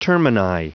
Prononciation du mot termini en anglais (fichier audio)
Prononciation du mot : termini